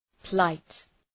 Προφορά
{plaıt}